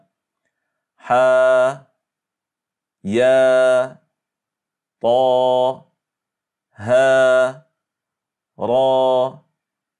Det är sammanlagt fem bokstäver (حَيٌ طَهُر ح, ى, ط, هـ , ر), som innehåller en madd-bokstav i stavningen på sitt bokstavsnamn och följs inte av en hamza eller sukun, var och en uttalas som två bokstäver, den andra av dem är en madd-bokstav som förlängs (2) ĥarakat som naturlig madd (madd aşli — المَد الأَصْلِي), som: